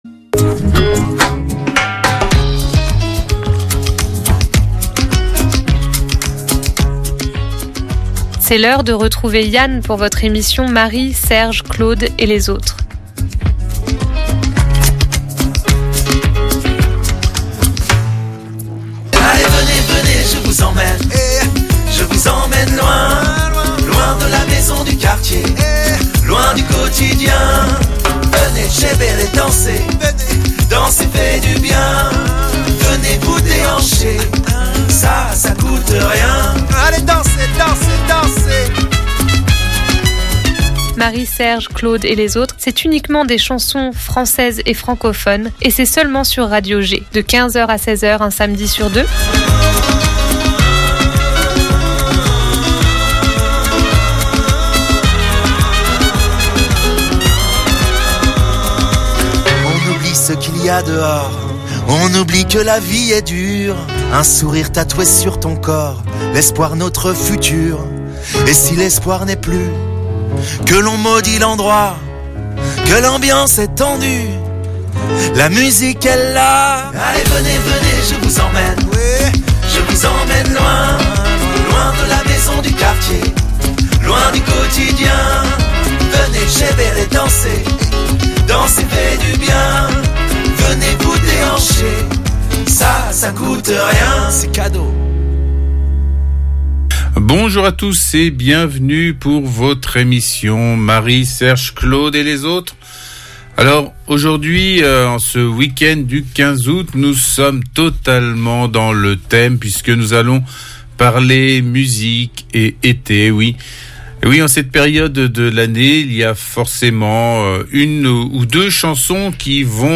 une heure de chansons françaises